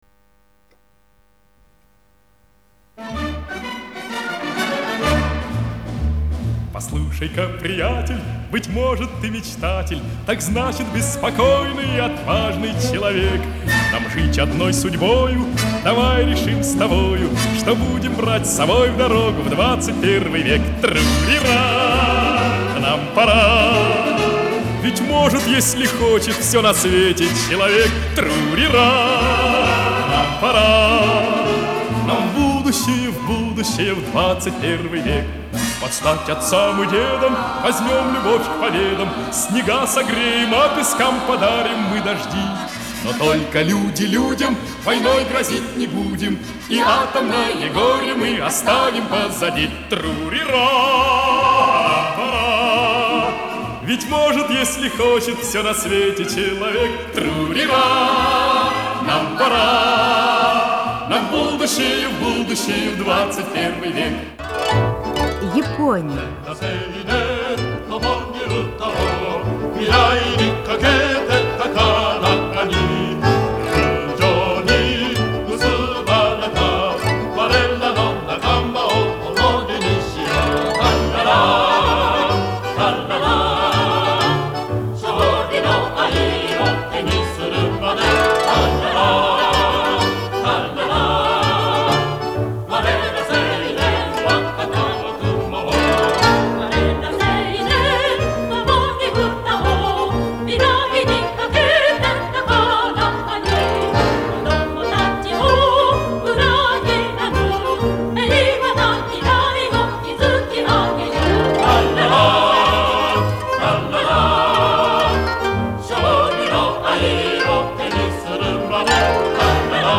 У микрофона эстрадный квинтет